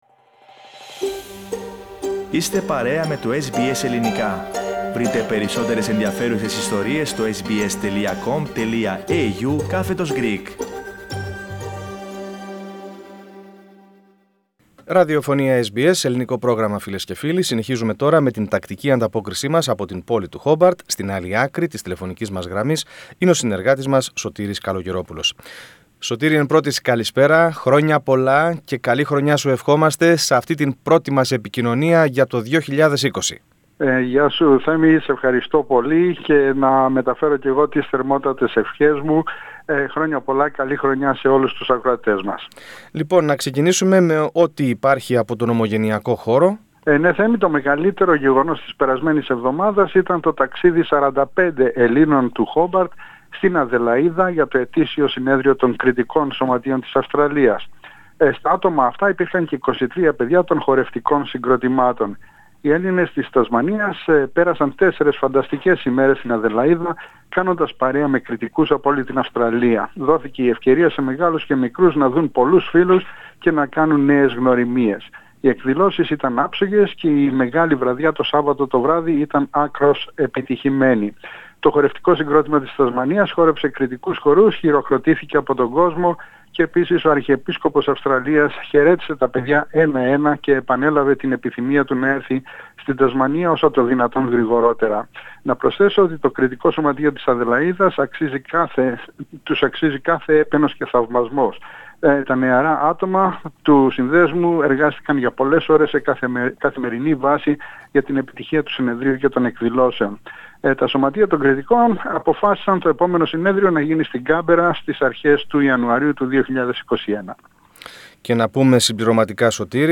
Πρώτη ανταπόκριση στο 2020 από το Χόμπαρτ της Τασμανίας (07.01.2020).